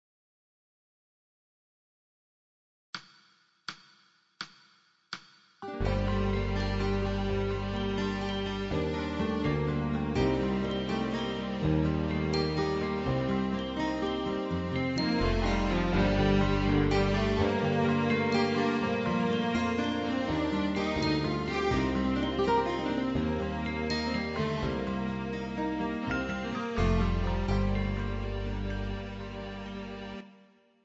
INSTRUMENTAL
Pan Flute